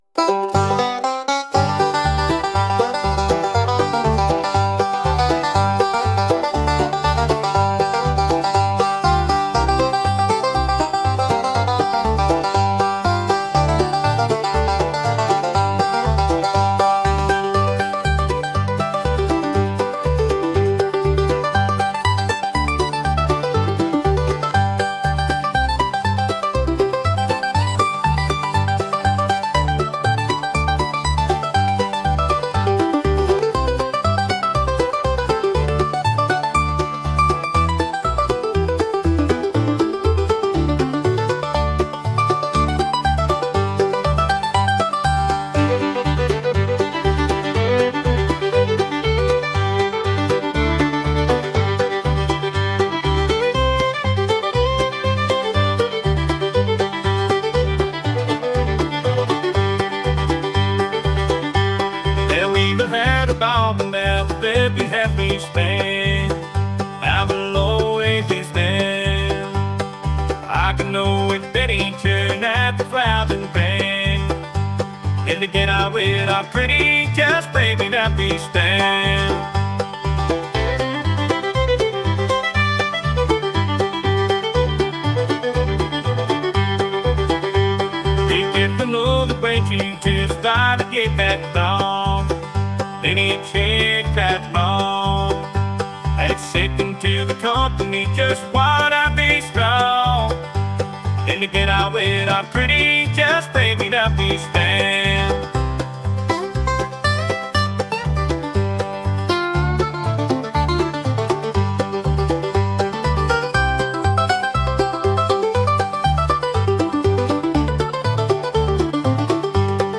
acoustic | energetic